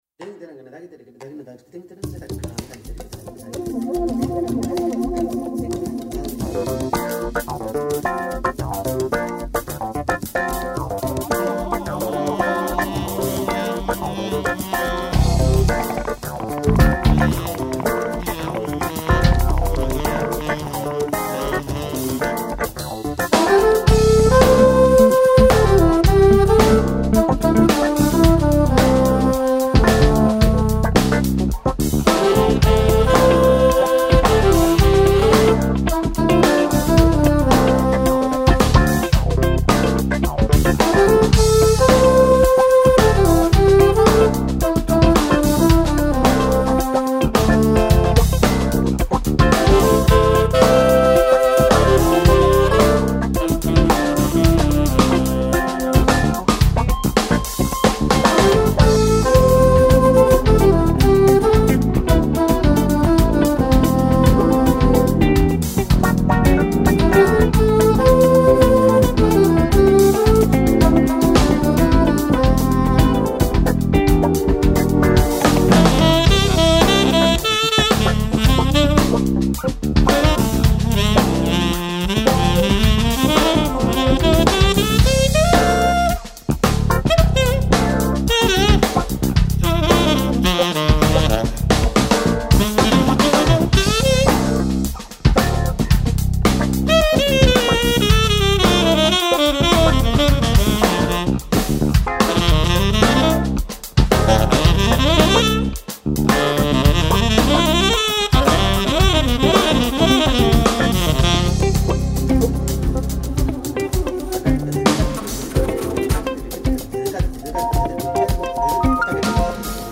1169   05:17:00   Faixa:     Jazz
Bateria
Saxofone Tenor, Sax Soprano, Flauta
Piano Acústico